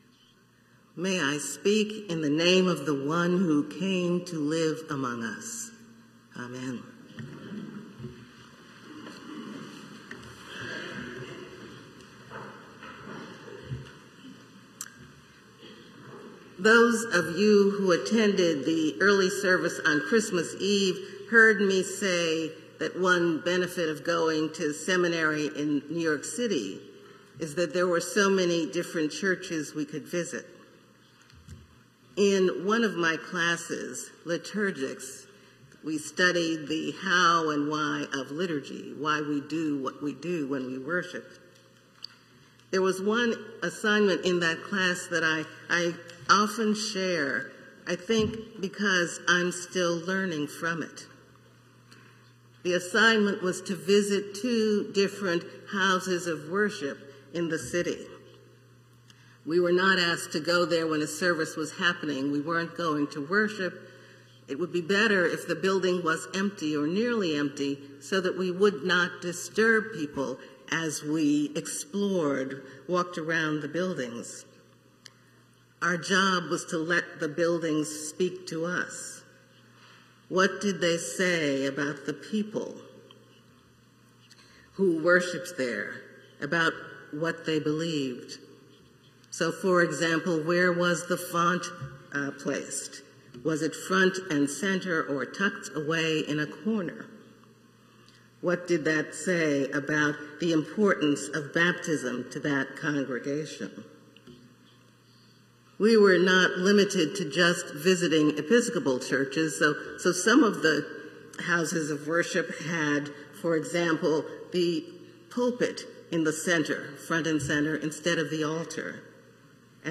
preaches on the first Sunday after Christmas.